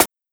Ordinary Life Snare.wav